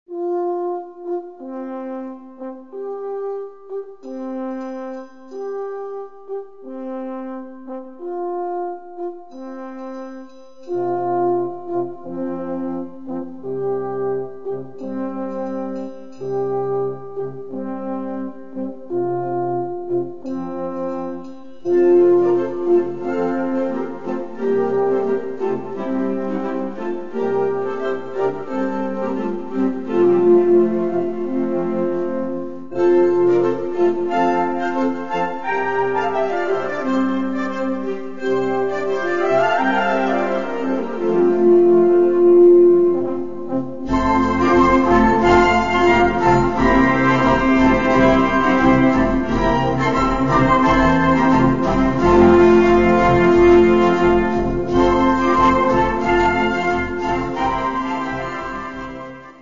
Gattung: Konzertmarsch
5:30 Minuten Besetzung: Blasorchester Zu hören auf